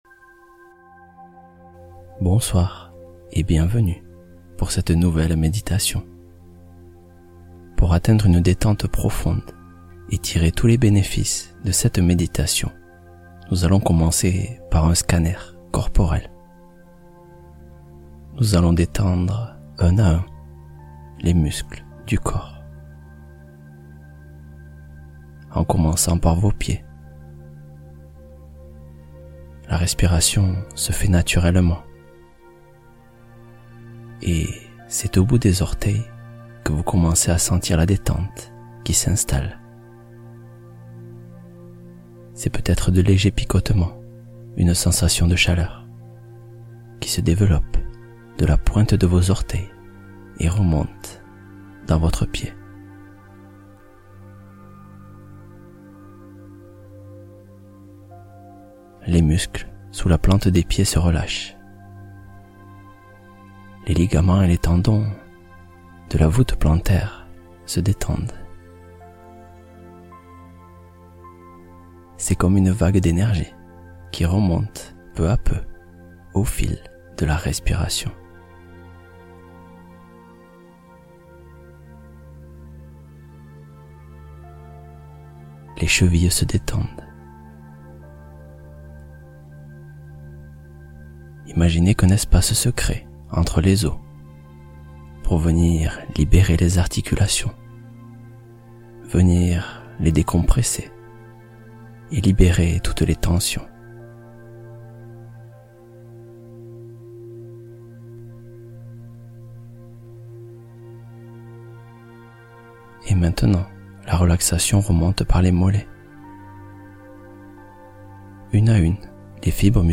Le Village Volant : Conte onirique pour un endormissement profond